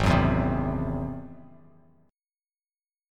G#+7 chord